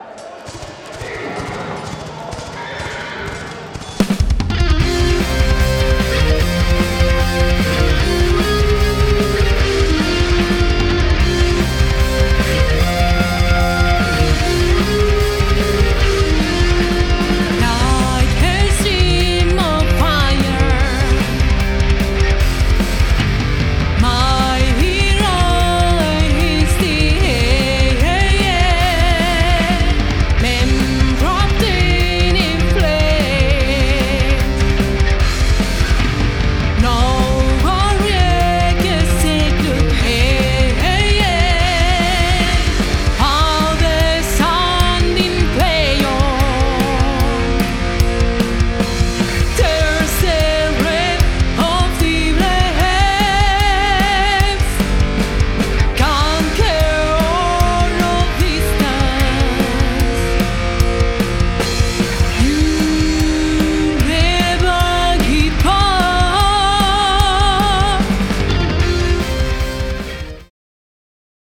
Power/Epic metal mixing - Feedback needed